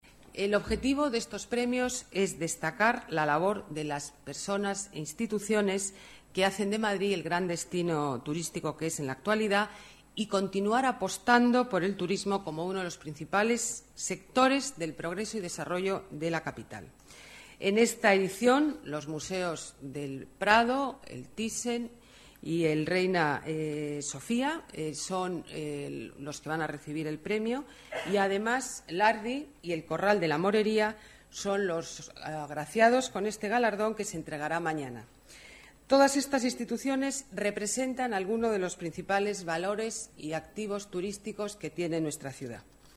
Nueva ventana:Declaraciones alcaldesa de Madrid, Ana Botella: Semana Mundial Turismo